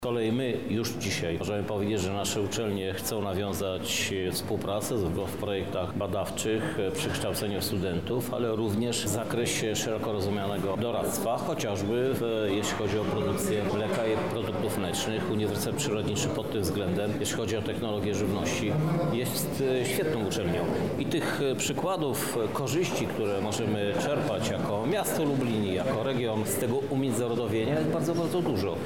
Krzysztof Żuk– mówi Krzysztof Żuk, Prezydent Miasta Lublin